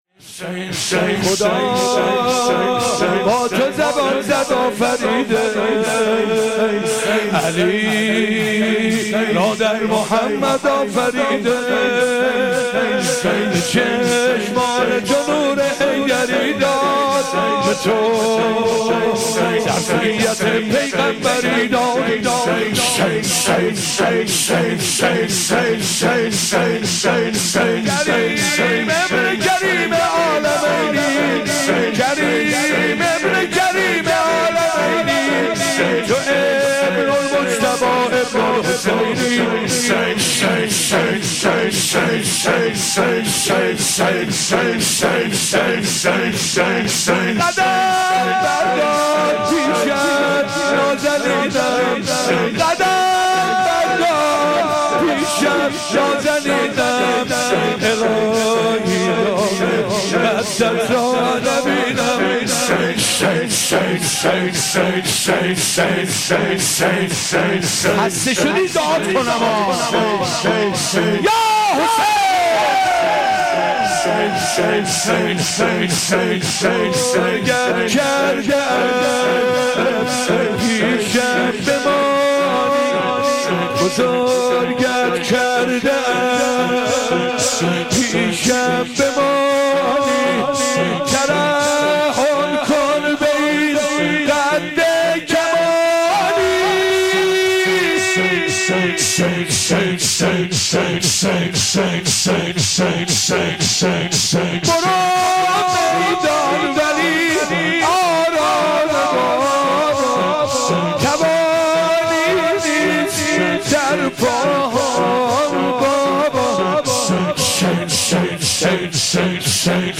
شور - علی را در محمد آفریده